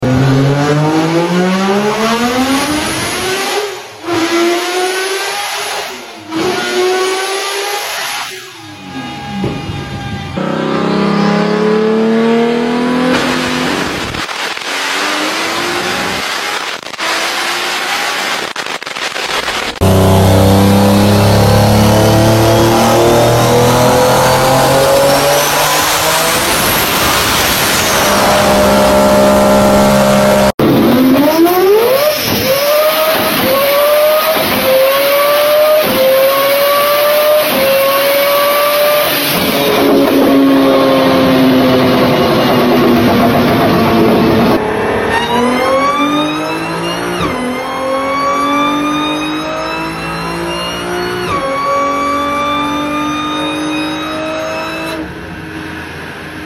Top 5 Craziest Dyno Pulls Sound Effects Free Download